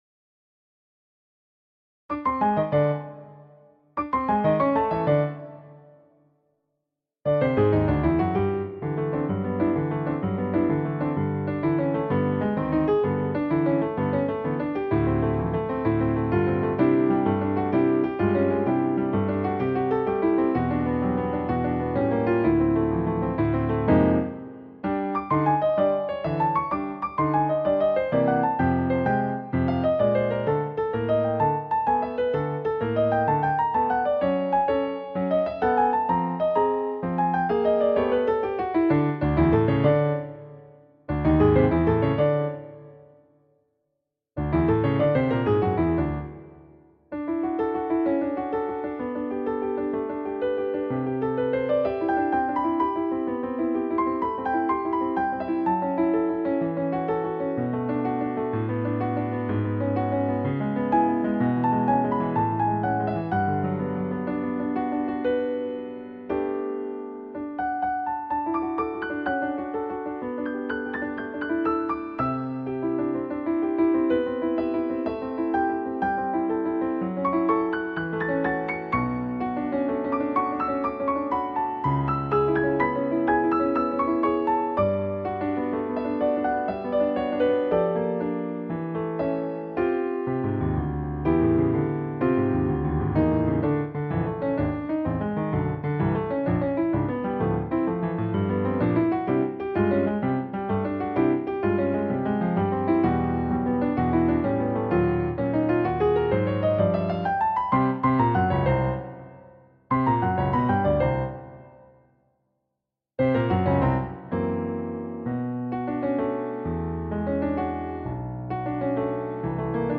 Games piano full speed